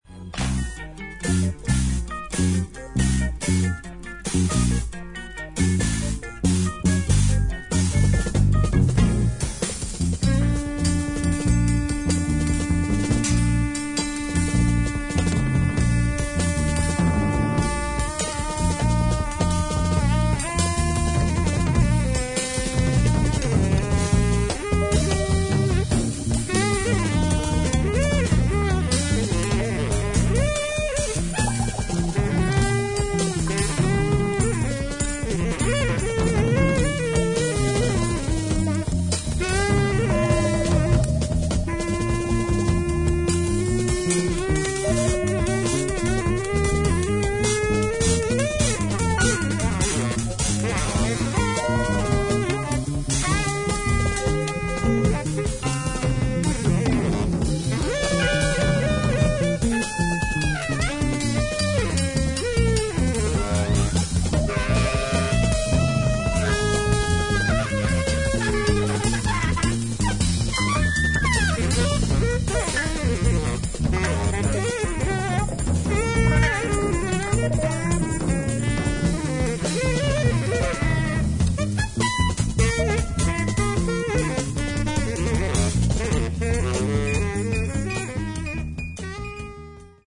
ジャズやプログレッシブロックの要素に加え、スコア中にインプロヴィゼーションも織り交ざるイギリスの前衛ロック名盤